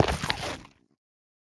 Minecraft Version Minecraft Version 1.21.5 Latest Release | Latest Snapshot 1.21.5 / assets / minecraft / sounds / block / netherrack / step4.ogg Compare With Compare With Latest Release | Latest Snapshot
step4.ogg